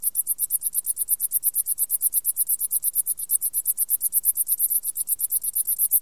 Genres: Animals (30) - Insect (18)